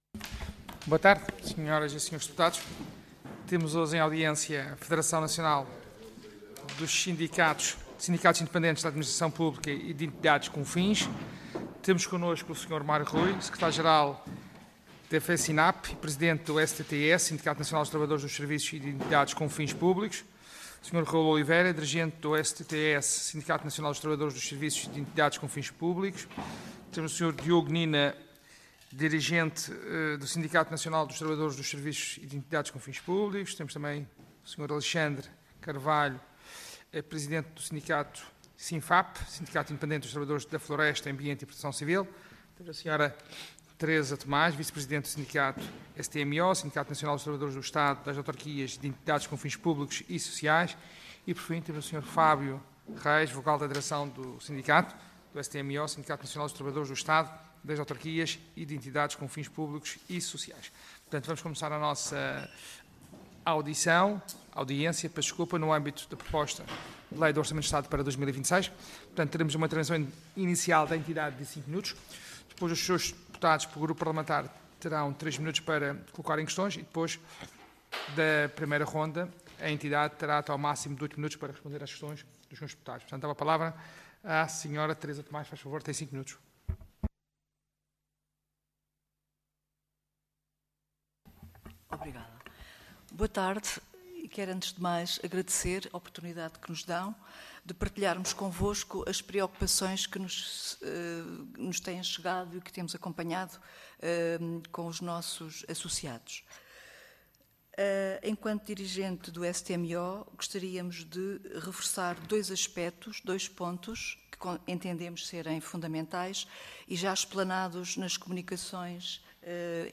Comissão de Orçamento, Finanças e Administração Pública Audiência Parlamentar Nº 8-COFAP-XVII Assunto Audiência da Federação Nacional de Sindicatos Independentes da Administração Pública e de Entidades com Fins, no âmbito da apreciação, na especialidade da Proposta de Lei n.º 37/XVII/1.ª (GOV) – Orçamento do Estado para 2026.